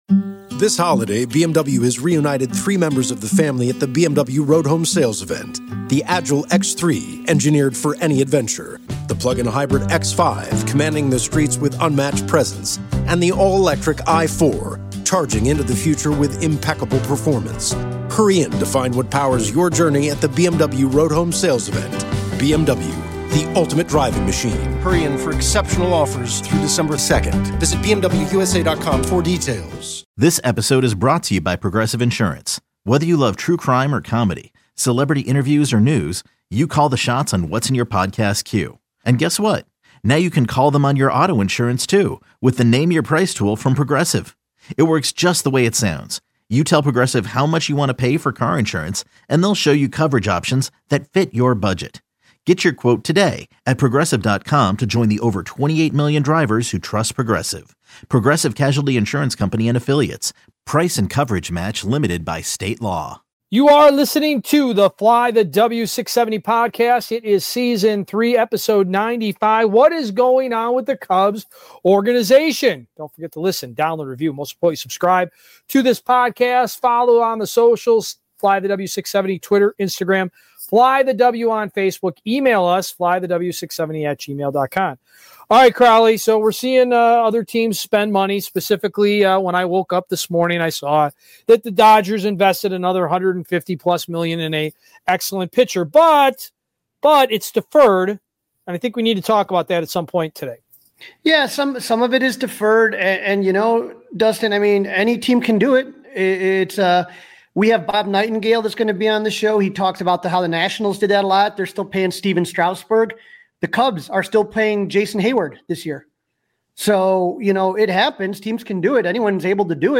fun, smart and compelling Chicago sports talk with great listener interaction. The show features discussion of the Bears, Blackhawks, Bulls, Cubs and White Sox as well as the biggest sports headlines beyond Chicago.